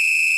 soft-sliderwhistle.ogg